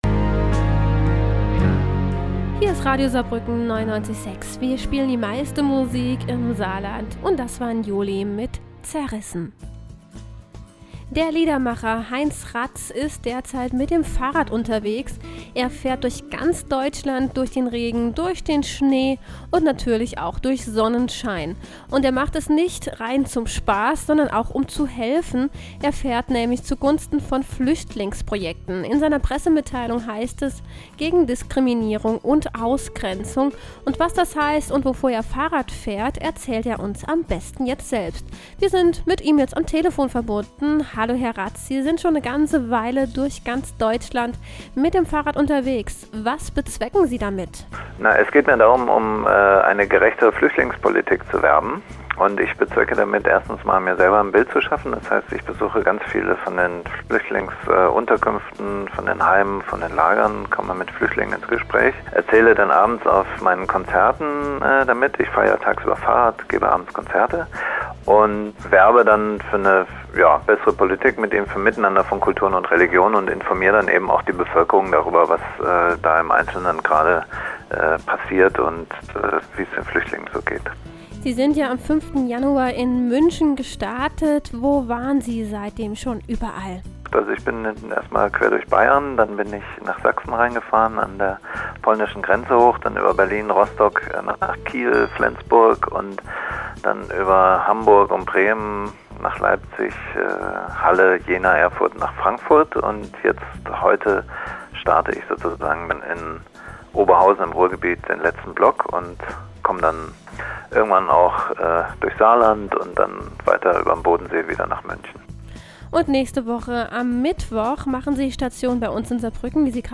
Interview Radio Saarbrücken